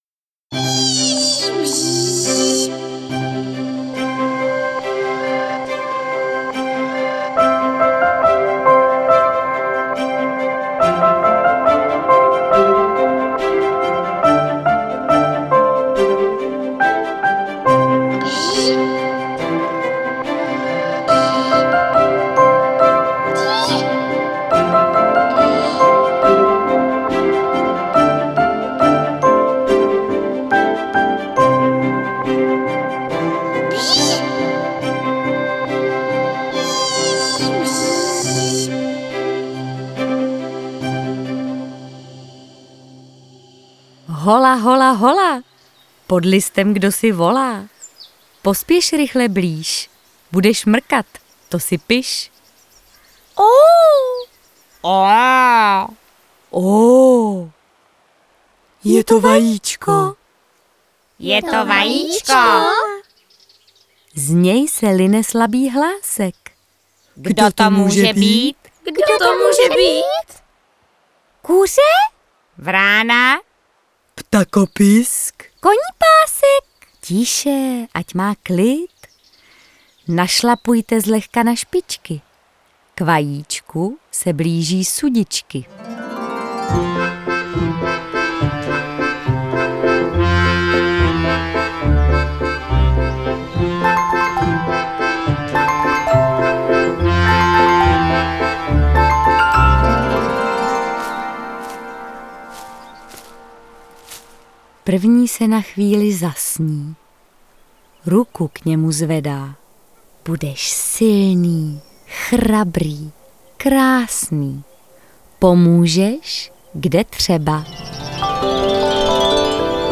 13 písní s vyprávěním (13 tracků):